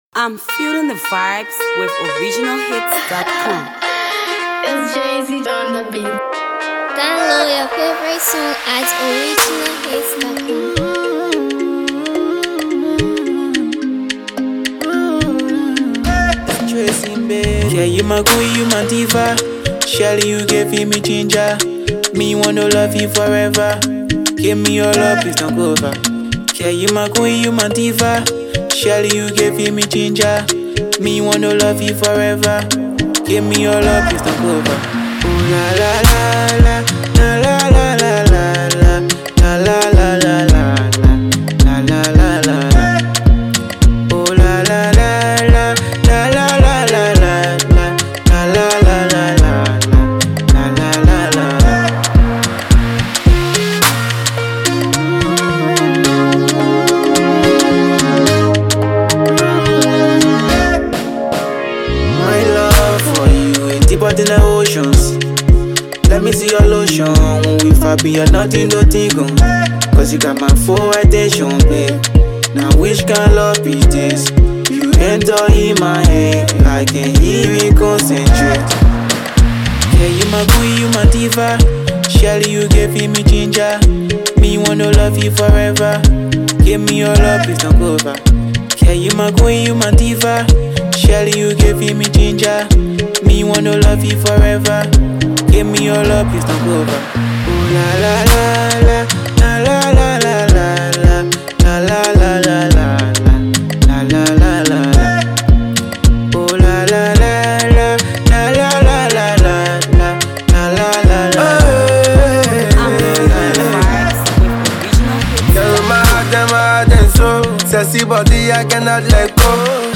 A nice piece of melody jam from uprising vocalist artist